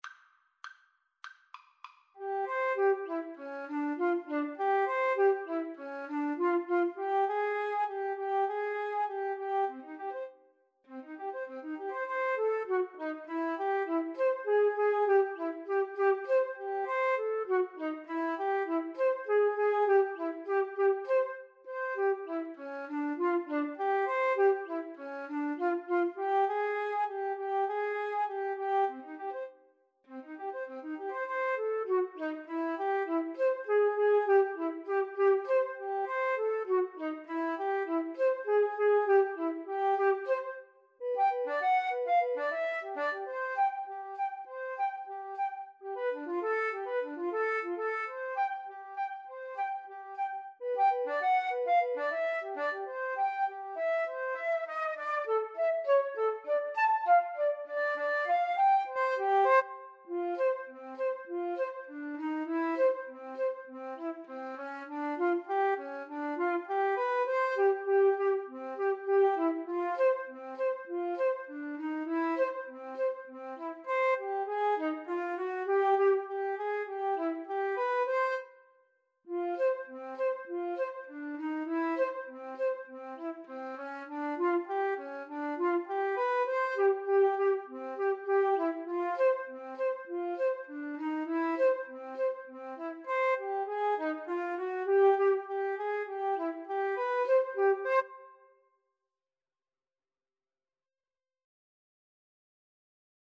Energico =200
Flute Duet  (View more Intermediate Flute Duet Music)
Jazz (View more Jazz Flute Duet Music)